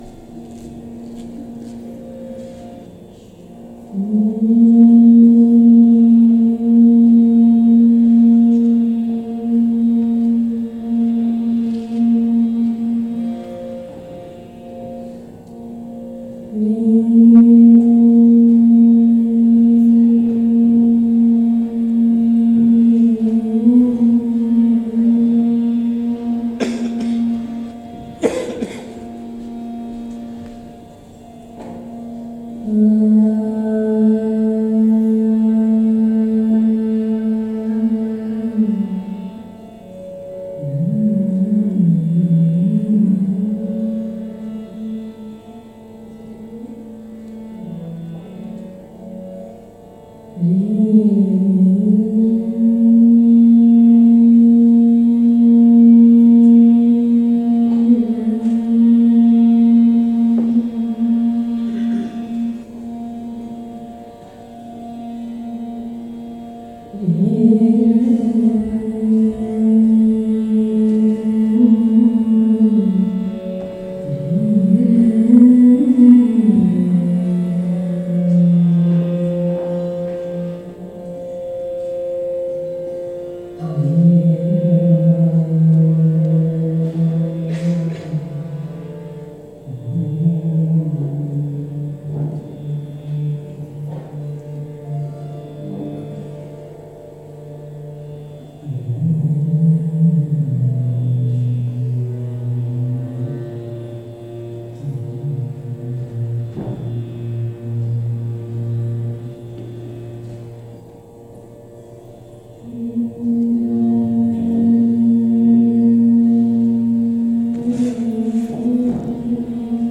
Hindustani classical vocal music